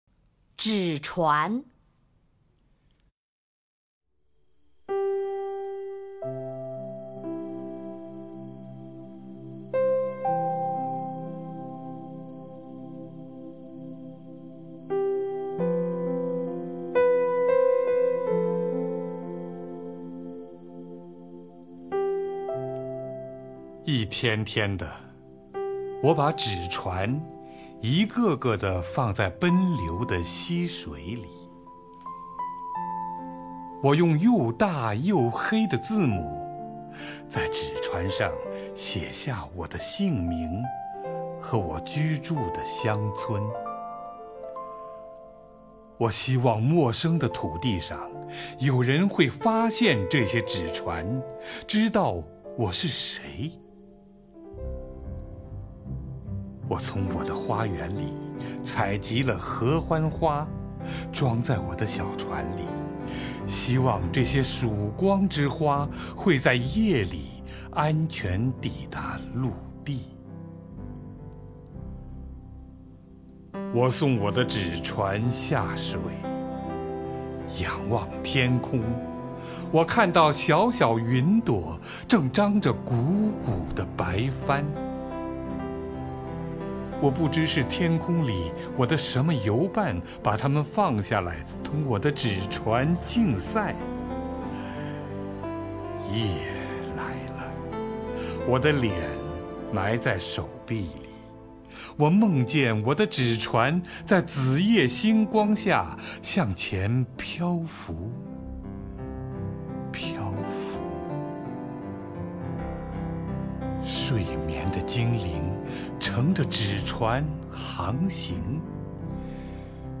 乔榛朗诵：《纸船》(（印度）拉宾德拉纳特·泰戈尔)
名家朗诵欣赏 乔榛 目录